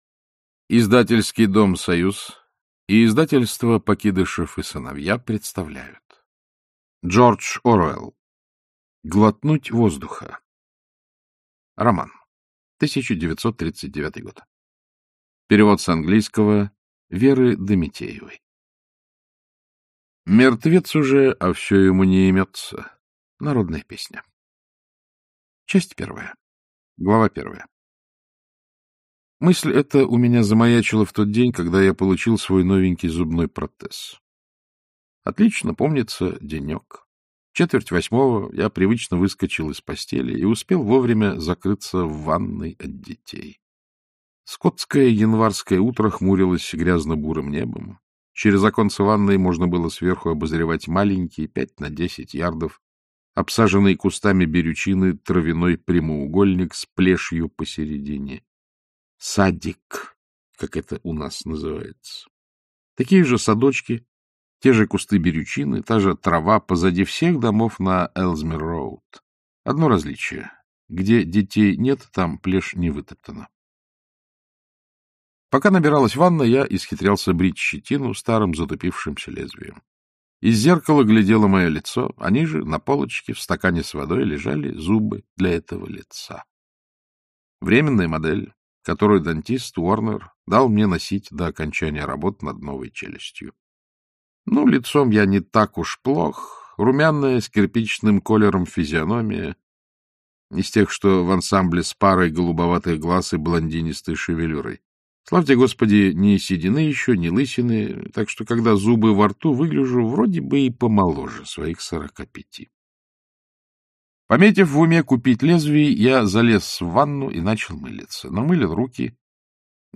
Аудиокнига Глотнуть воздуха - купить, скачать и слушать онлайн | КнигоПоиск